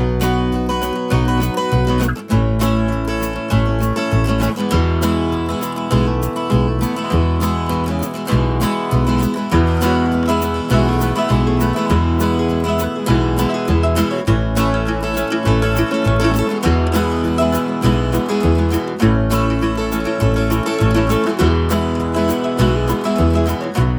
Minus Harmonica Pop (1960s) 2:51 Buy £1.50